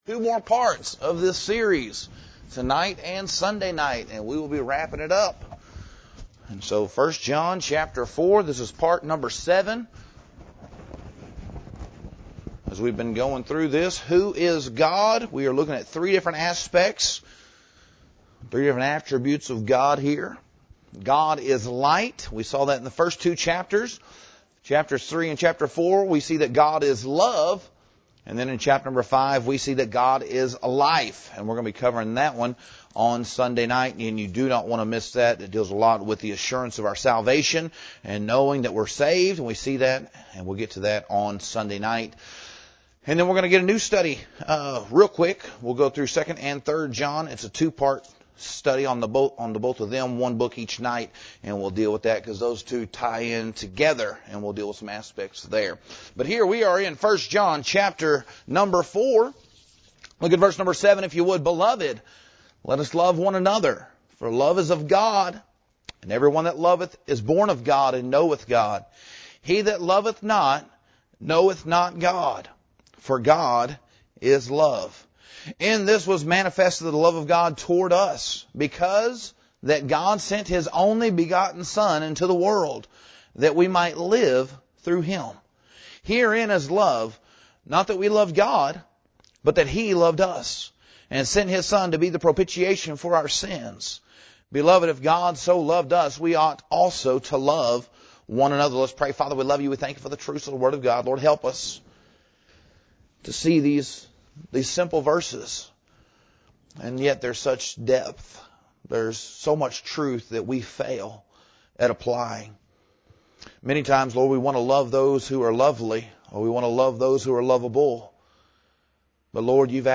Introduction Sermon Title